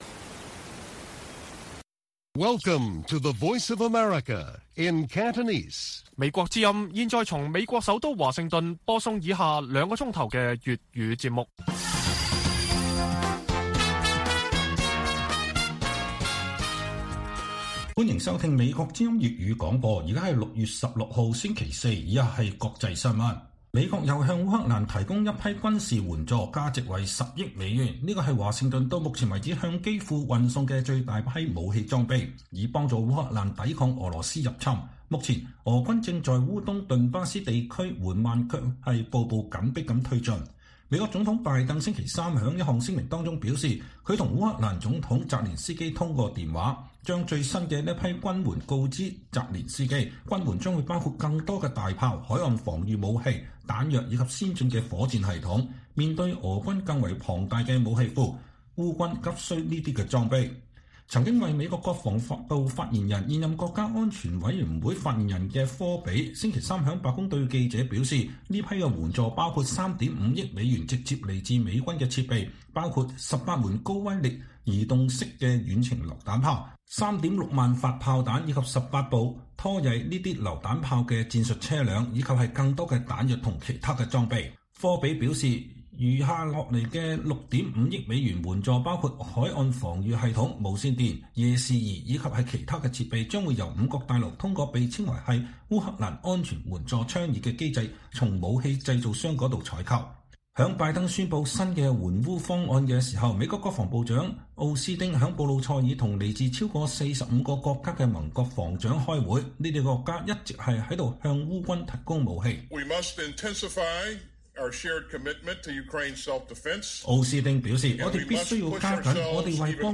粵語新聞 晚上9-10點: 美國宣布向烏克蘭提供10億美元新軍援